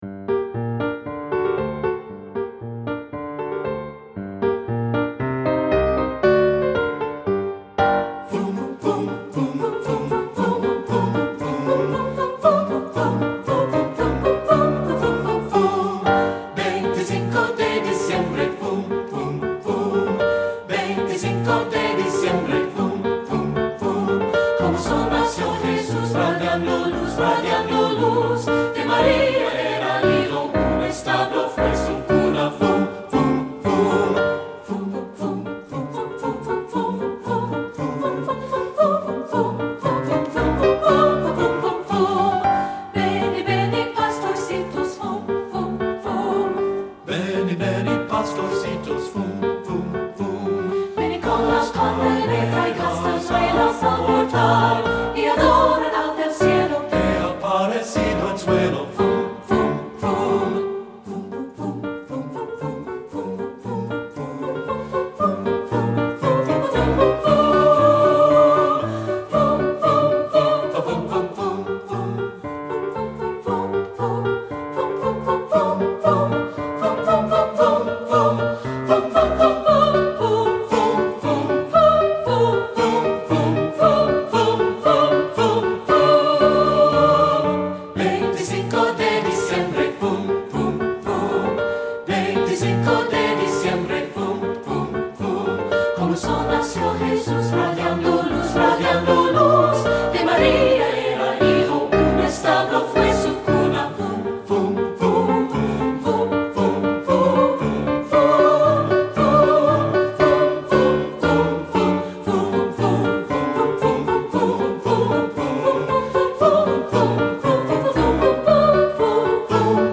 Composer: Spanish Carol
Voicing: SAB and Piano